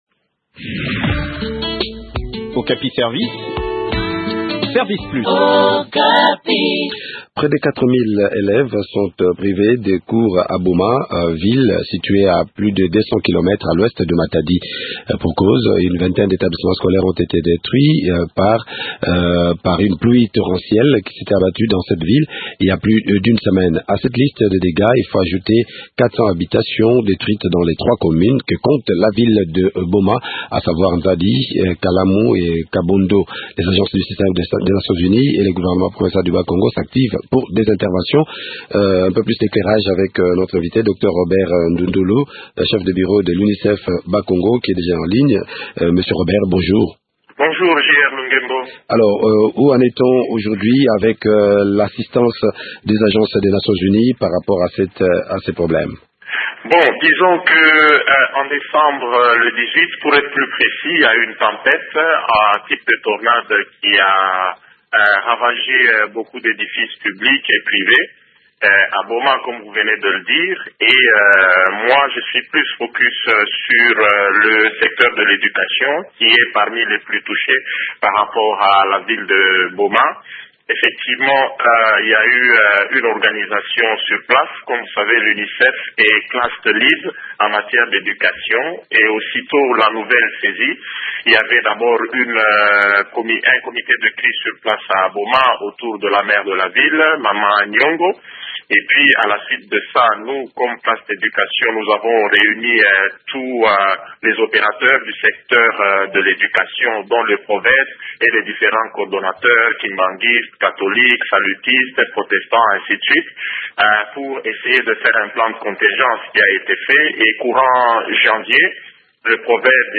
Le point sur de la situation sur le terrain dans cet entretien